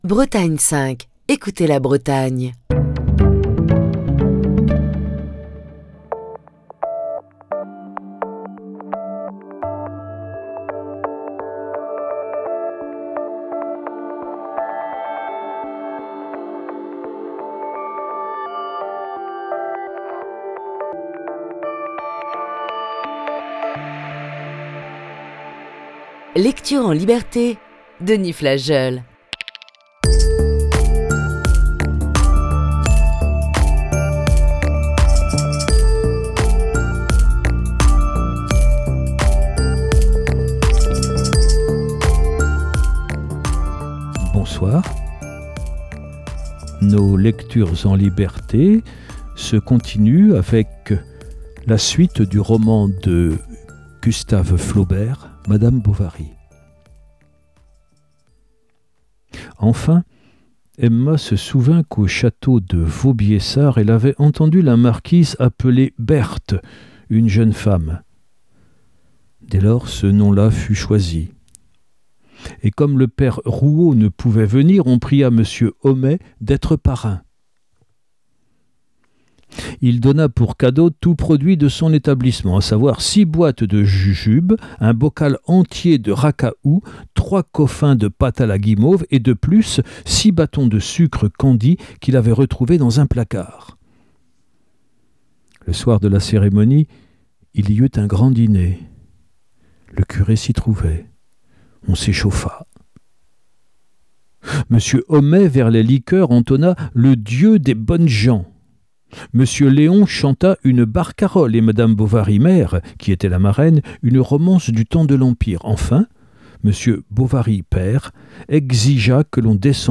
Émission du 24 septembre 2021.